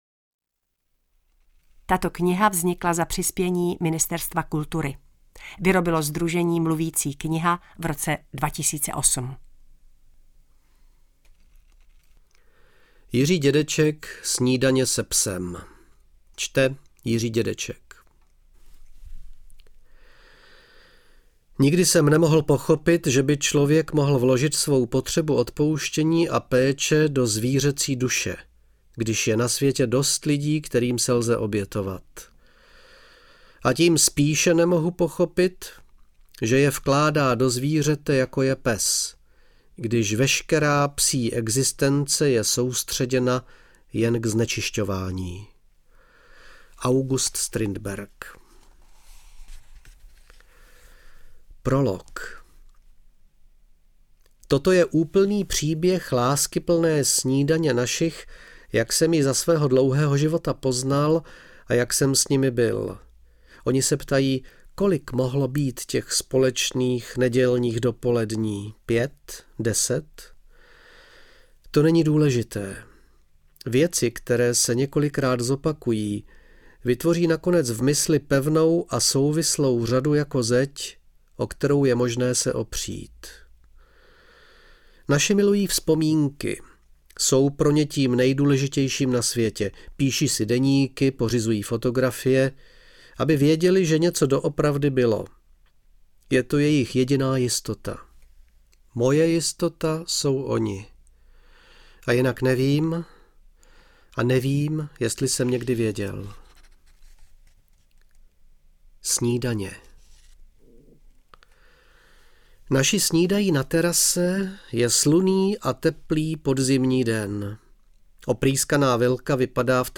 Čte: Jiří Dědeček